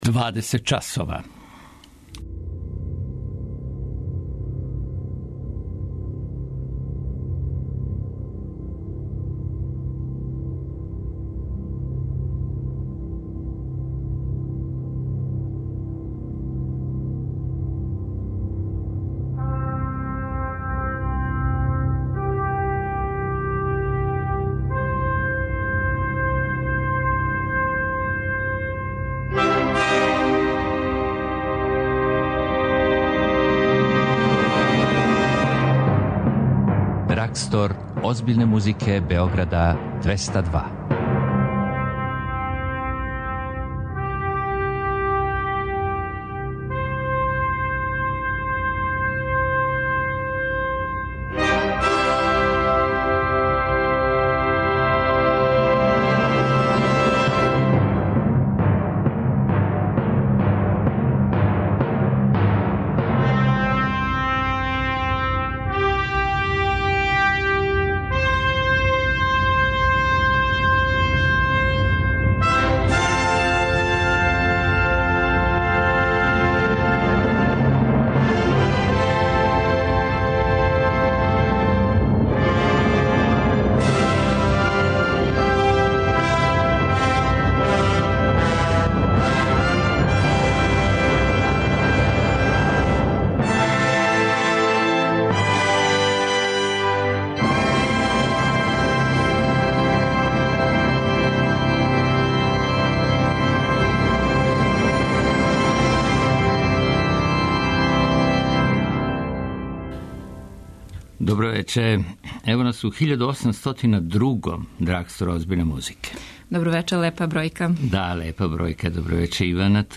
Елегични трио за клавир, виолину и виолончело